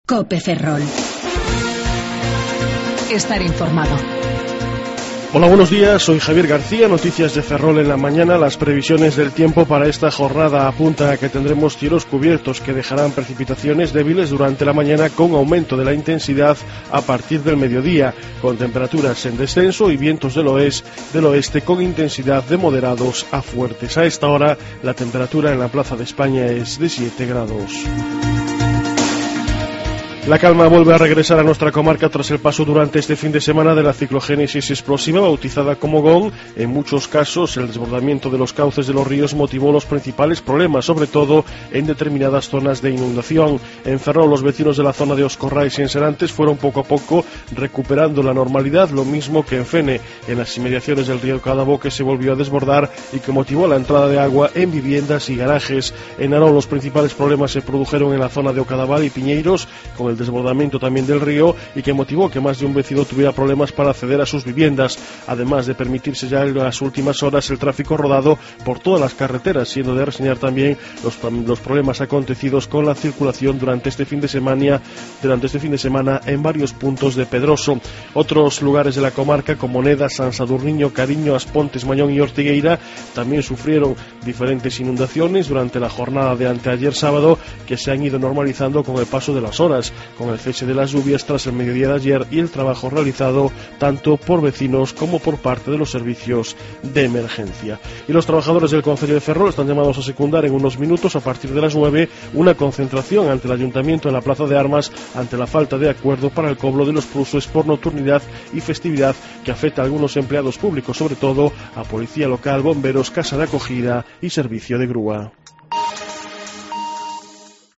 08:28 Informativo La Mañana